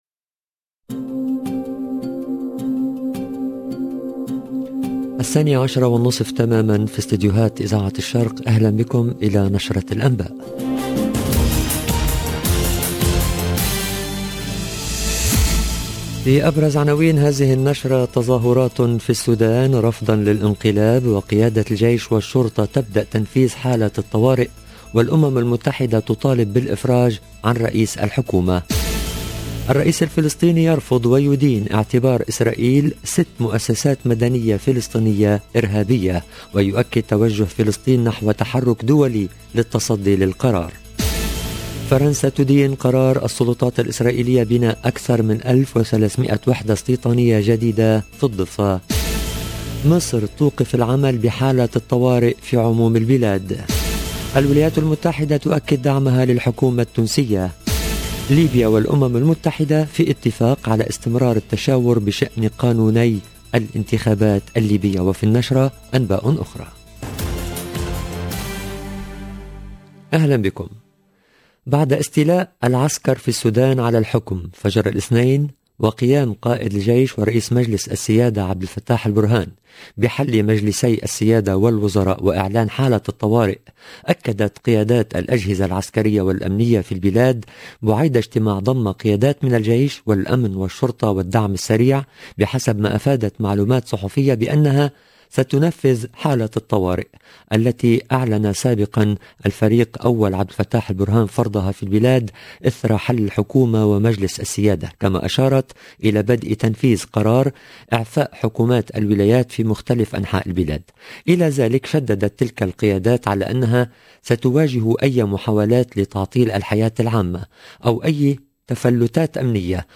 LE JOURNAL EN LANGUE ARABE DE 12H30 DU 26/10/2021